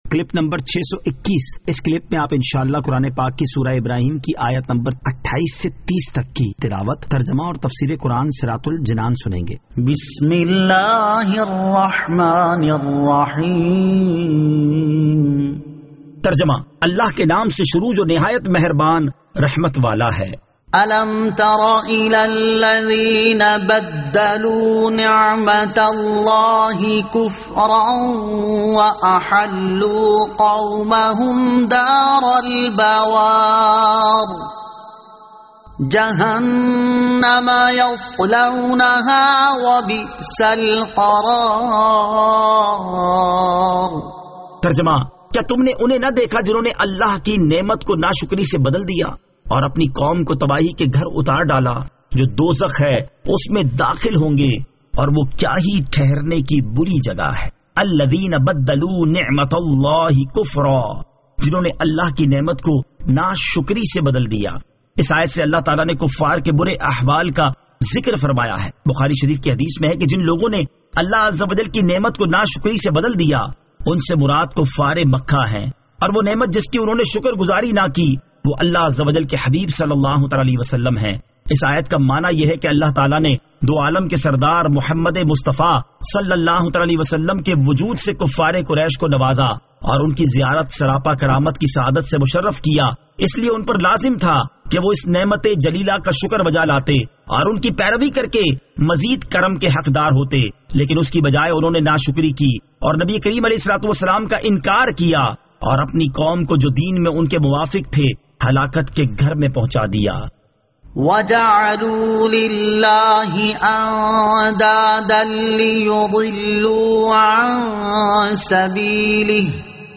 Surah Ibrahim Ayat 28 To 30 Tilawat , Tarjama , Tafseer